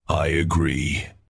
Subject description: Yuri Unit's voice pack with a style that leans towards bland, cold, and serious   Reply with quote  Mark this post and the followings unread